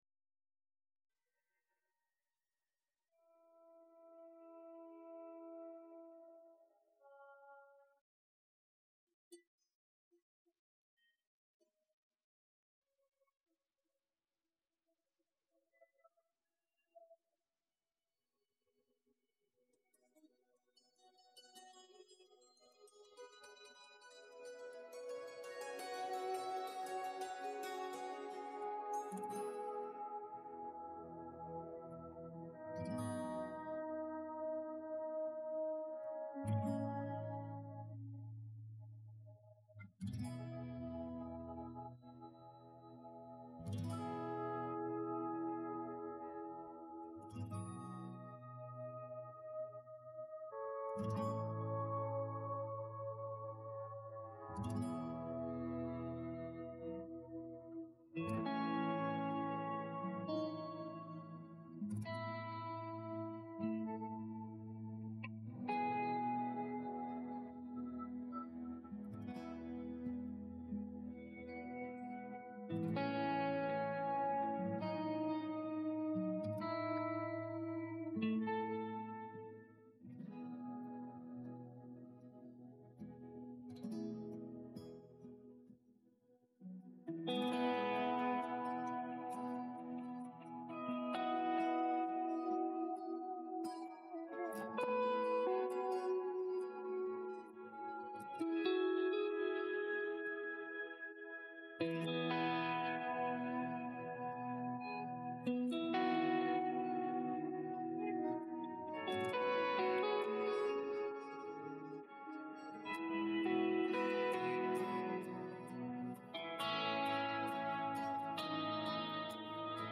Download Guitar Track